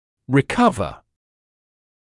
[rɪ’kʌvə][ри’кавэ]восстанавливать здоровье или здоровое, правильное состояние